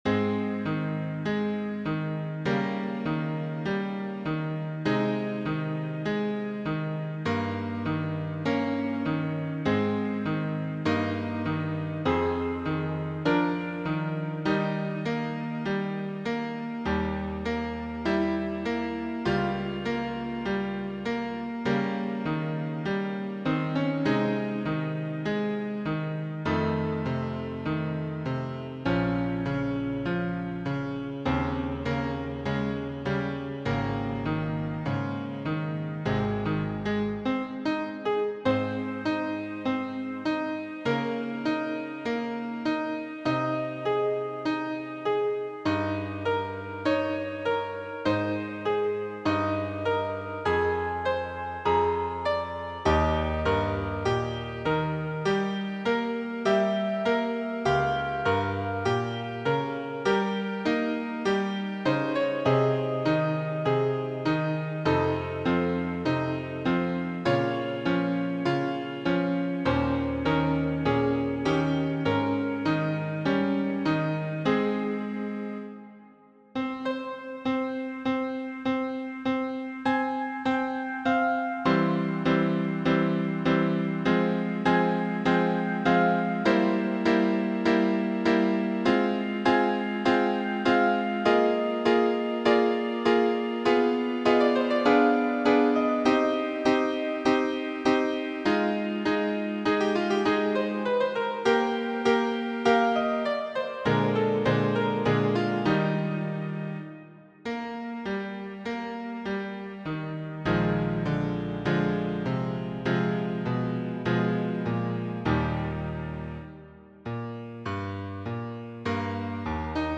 Muziko:
Patetika, parto de fama sonato de Beethoveno.